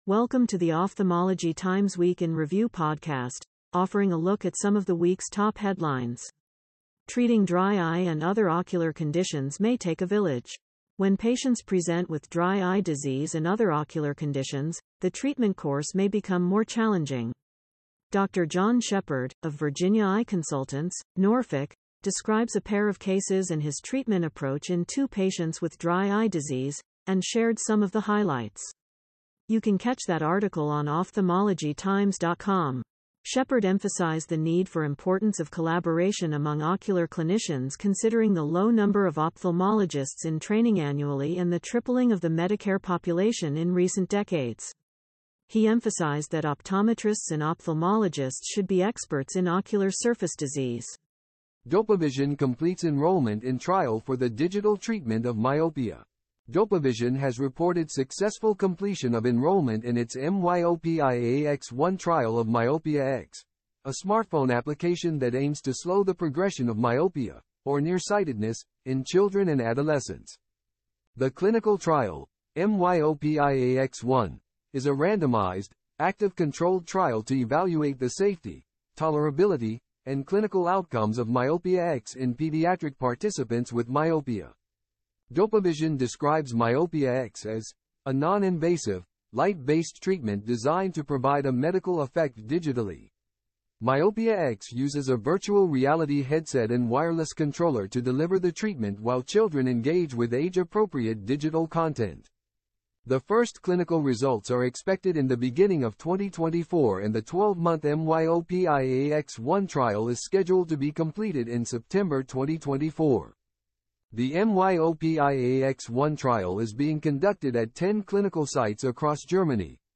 Editor's Note: This podcast was generated from Ophthalmology Times content using an AI platform.